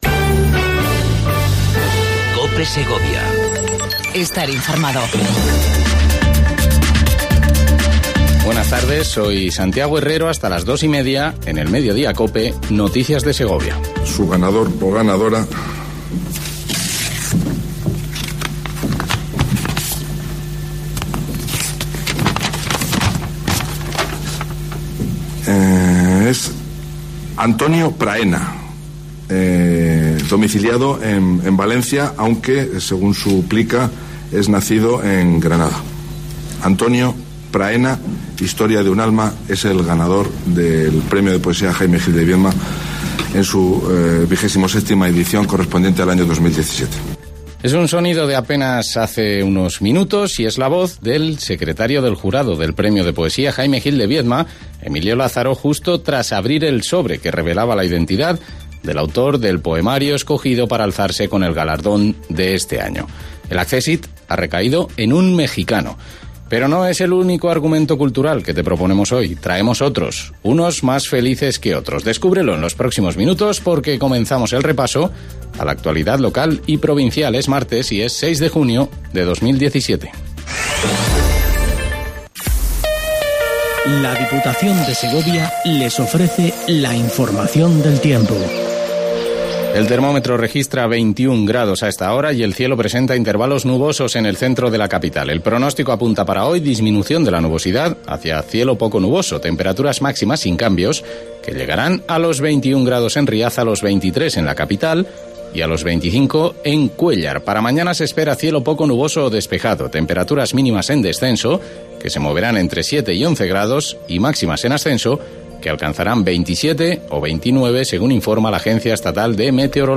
INFORMATIVO MEDIODIA COPE EN SEGOVIA 06 06 17